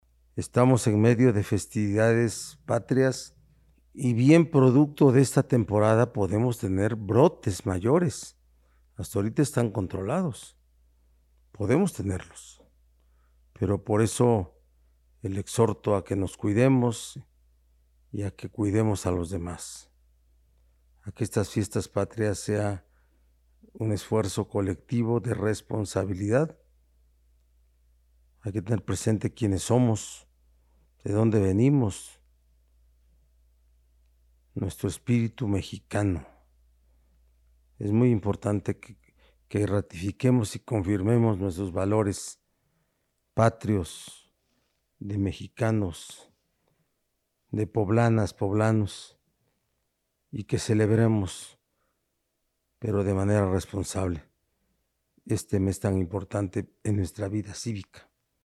En videoconferencia de prensa en Casa Aguayo, el titular del Ejecutivo señaló que hay que celebrar dichas fechas con todas las medidas de prevención, como el uso de cubrebocas y la sana distancia, pues es relevante la vida cívica del país.